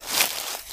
High Quality Footsteps
STEPS Bush, Walk 04.wav